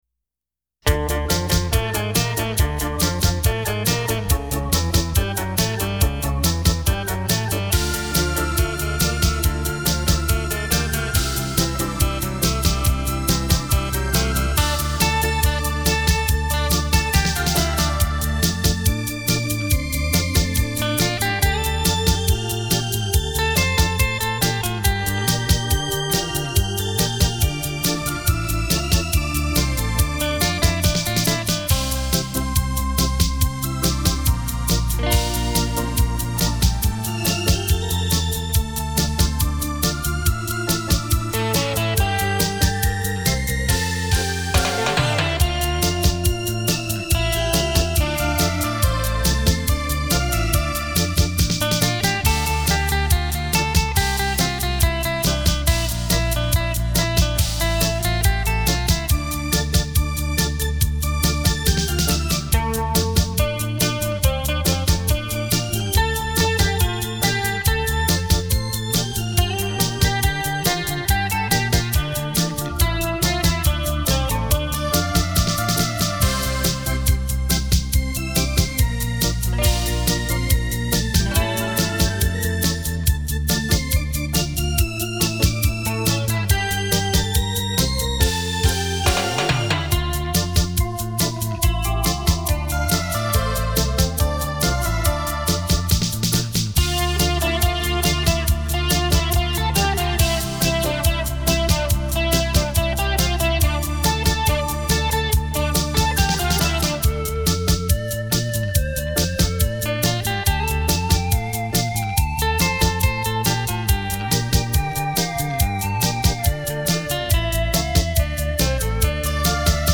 版主辛苦了，每集都音质超棒，旋律优美，很喜欢。
经典 优美的旋律 感谢楼主分享电子琴音乐
浪漫的电子琴音乐伴你快乐每一天。
很好听的双电子琴音乐，谢谢分享